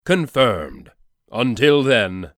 confirmation_01.mp3